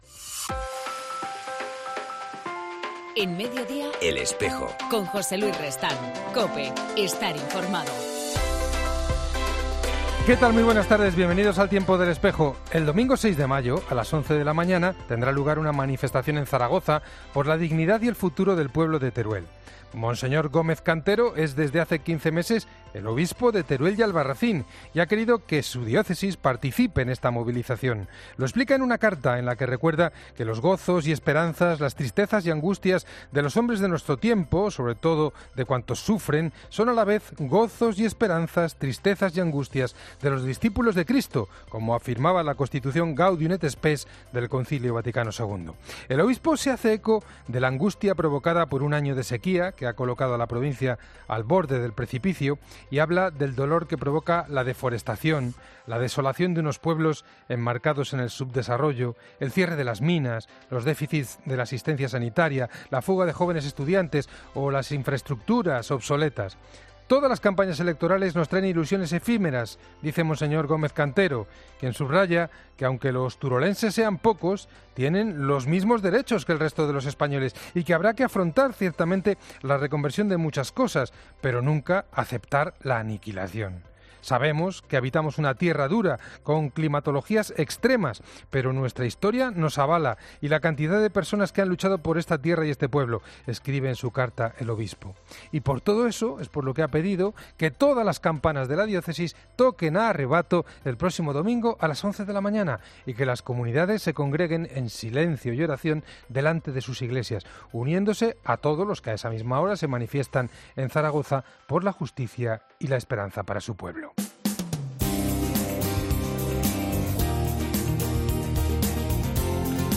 En El Espejo de hoy entrevistamos